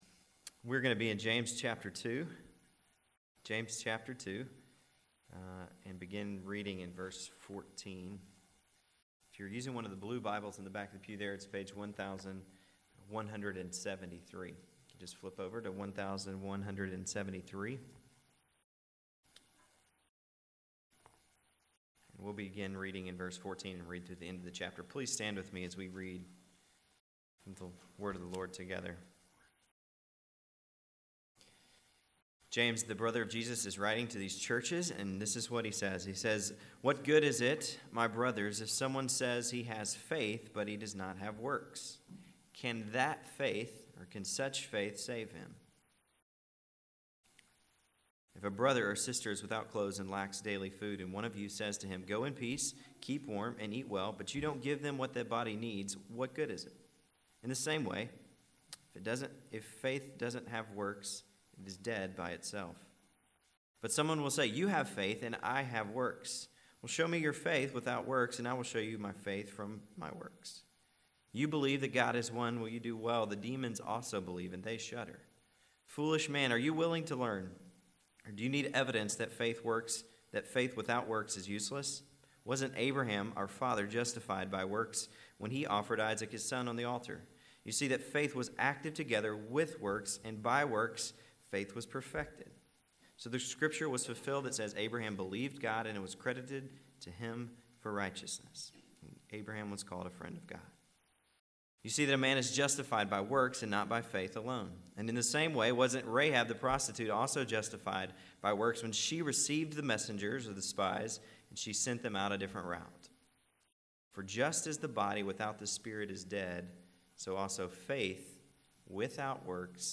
September 25, 2011 AM Worship | Vine Street Baptist Church
Before the offering was collected, the congregation sang “I Surrender All.”
At the conclusion of the sermon, the gathered sang “Faith is the Victory.”